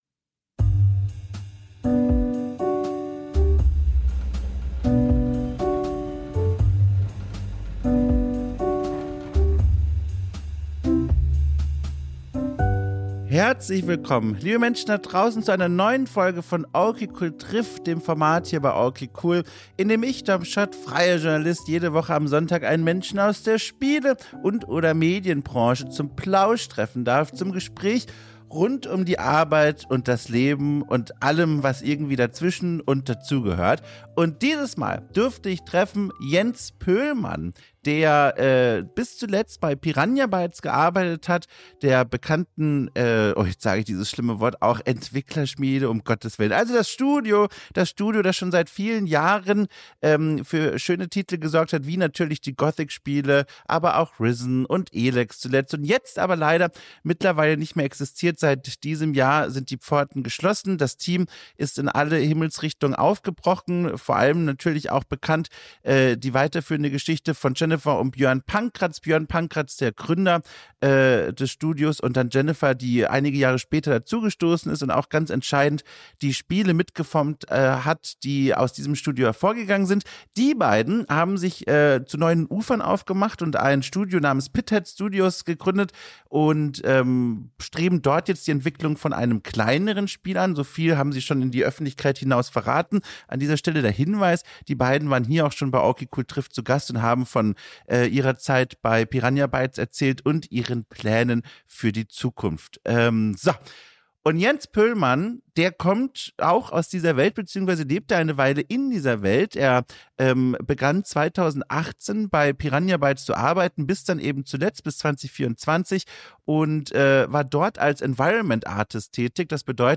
Ein Gespräch über die Arbeit und das Leben drumrum